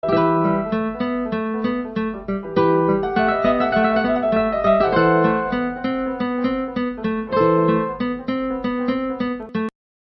合成音乐还可以转换成别的乐器
它可以将钢琴原声，转换成其他乐器的声音
例如，可以将钢琴音乐转换成吉他的声音。